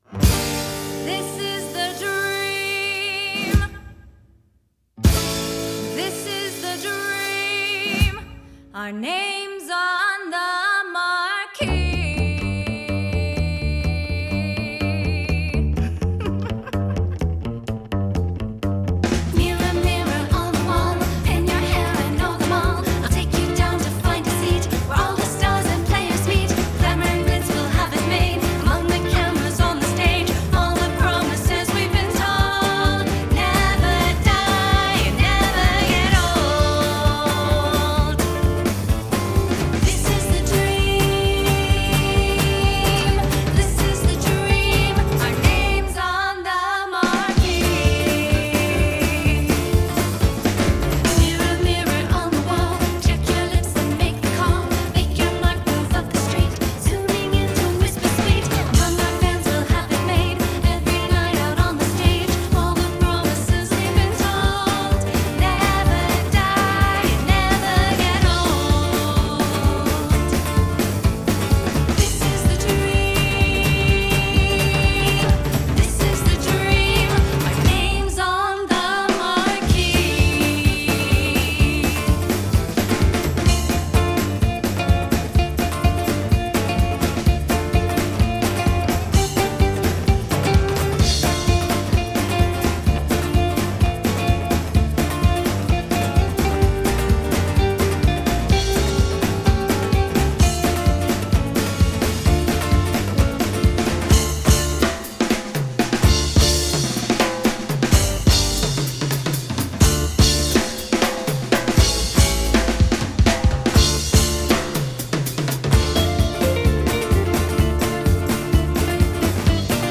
standup bass, piano, guitar, vocals
drums, percussion.
Genre: Jazz Vocals, Gipsy Swing